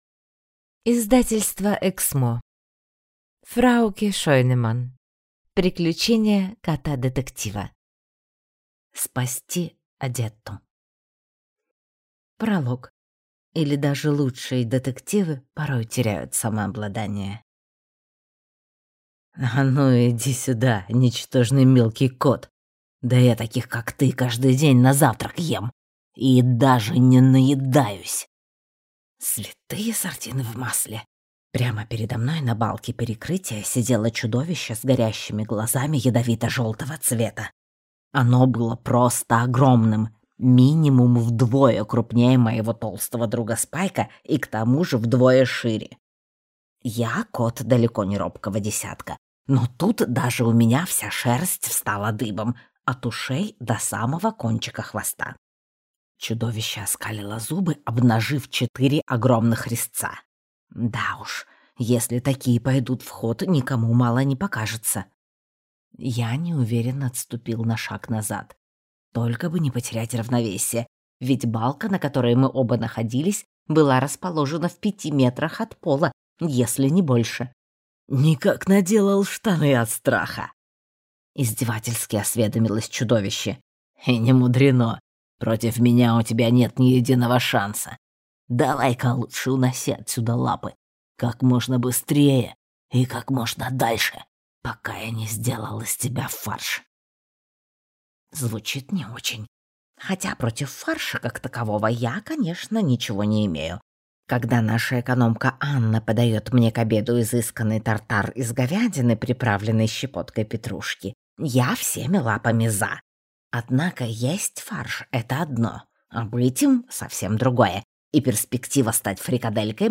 Аудиокнига Спасти Одетту | Библиотека аудиокниг